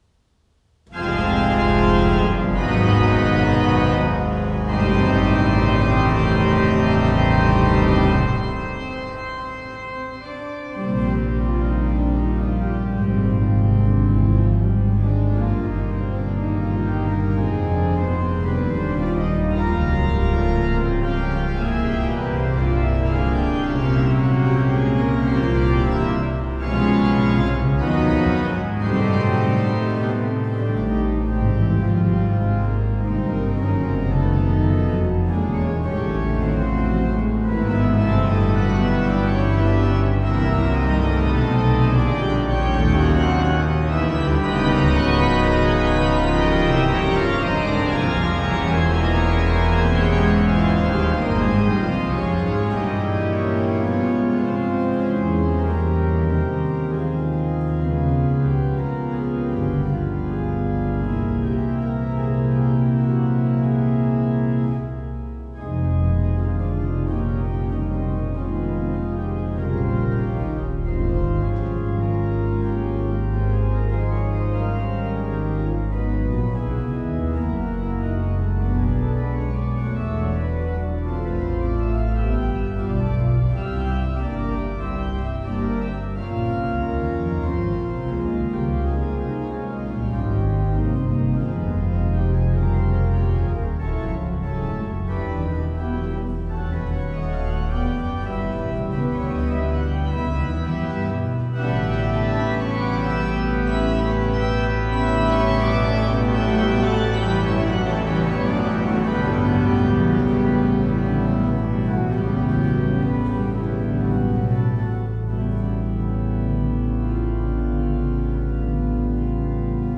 The recording has not been edited